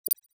Holographic UI Sounds 62.wav